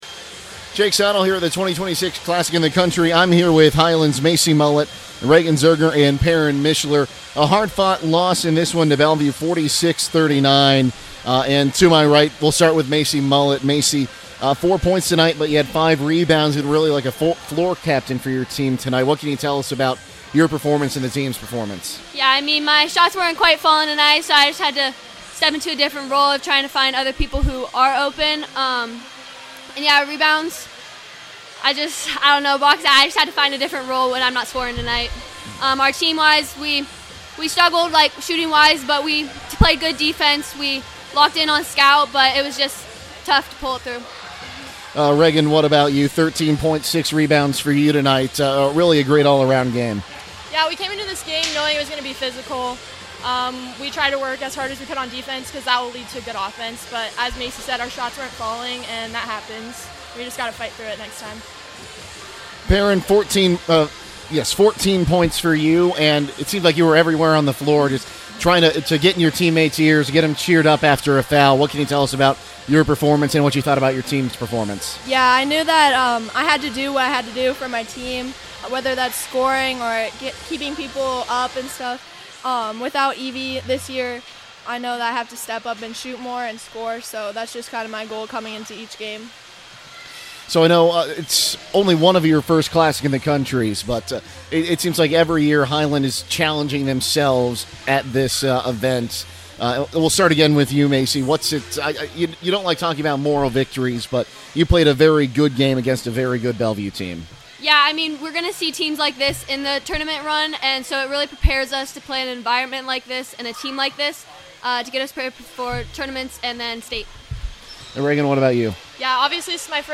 CLASSIC 2026 – HILNAD PLAYERS INTERVIEW